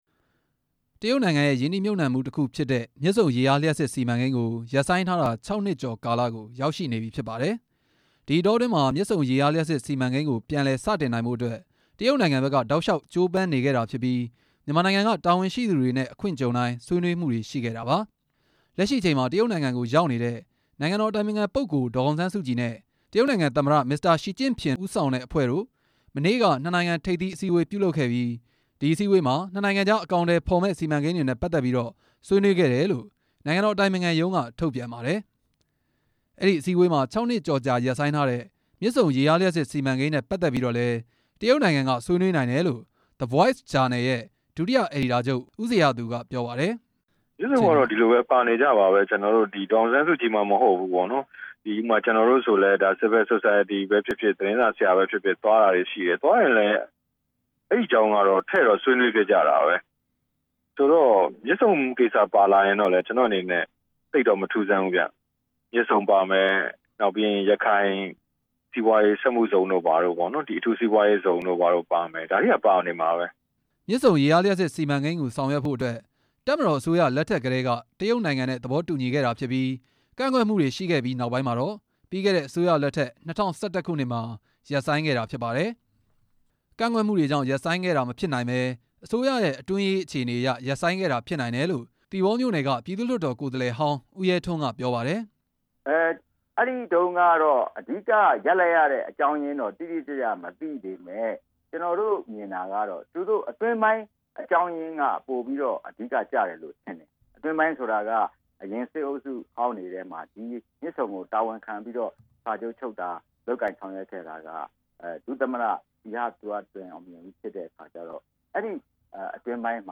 နိုင်ငံရေး လေ့လာသုံးသပ်သူတွေနဲ့ နိုင်ငံရေး သမားအချို့ရဲ့ အမြင်ကို